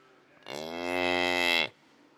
cow-moan-2-96khz.wav